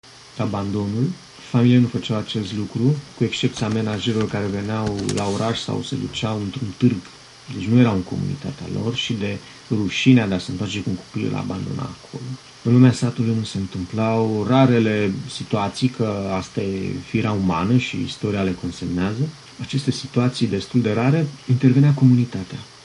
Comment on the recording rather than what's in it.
Here’s a recording a part of a radio programme in a mystery language.